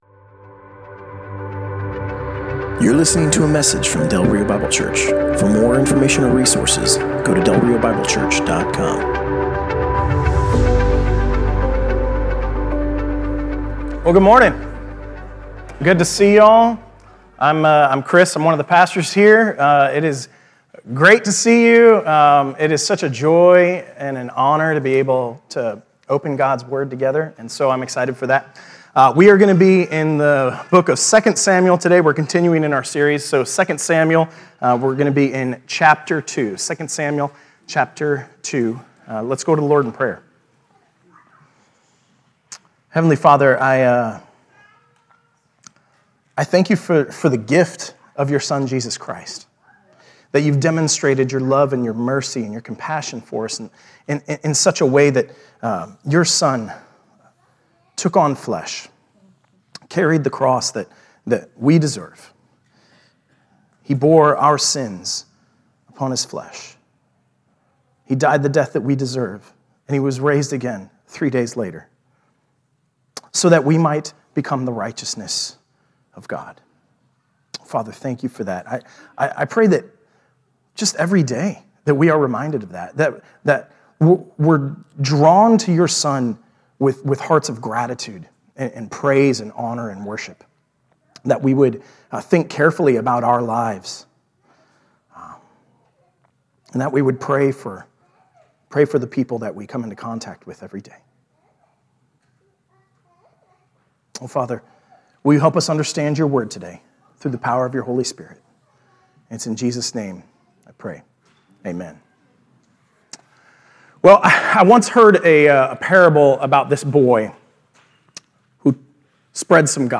Passage: 2 Samuel 2: 1-17 Service Type: Sunday Morning